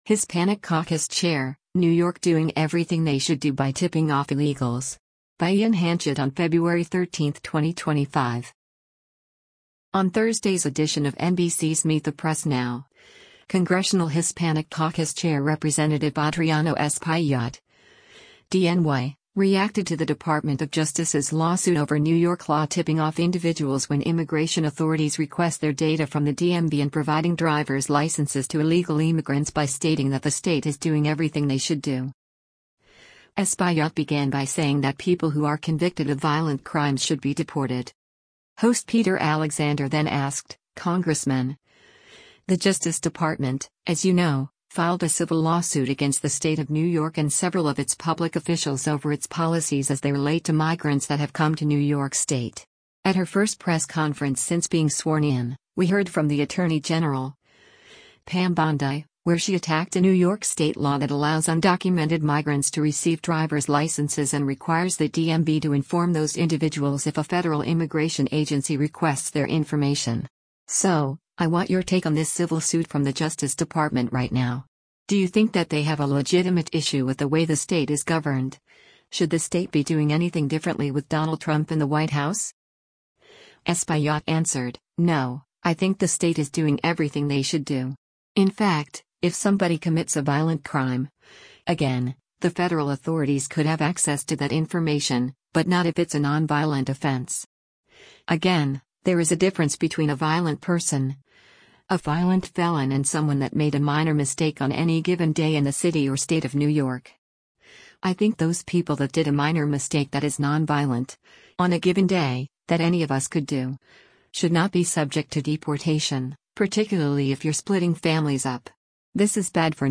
On Thursday’s edition of NBC’s “Meet the Press Now,” Congressional Hispanic Caucus Chair Rep. Adriano Espaillat (D-NY) reacted to the Department of Justice’s lawsuit over New York law tipping off individuals when immigration authorities request their data from the DMV and providing driver’s licenses to illegal immigrants by stating that “the state is doing everything they should do.”